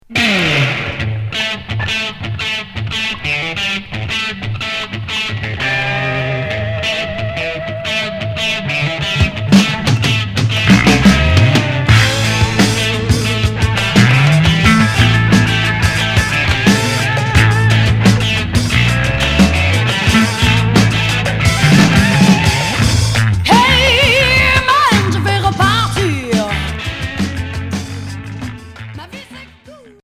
Heavy rock Quatrième 45t retour à l'accueil